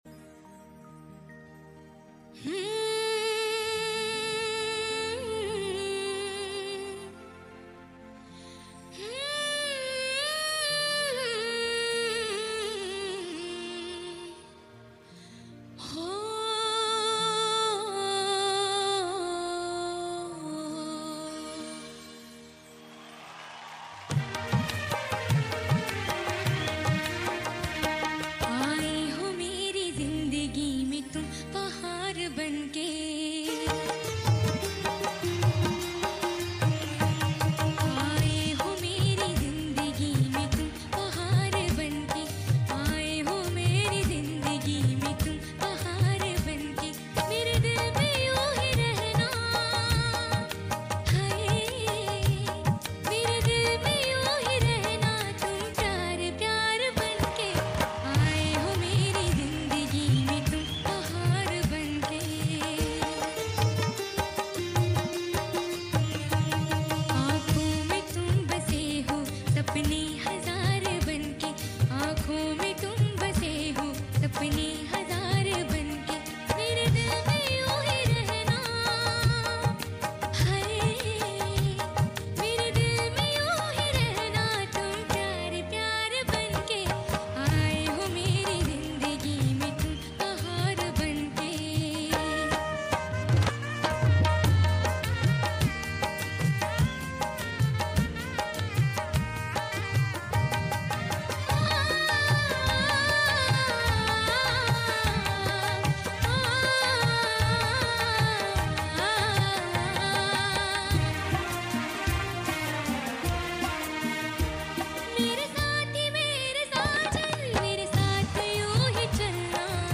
in his melodious voice on the stage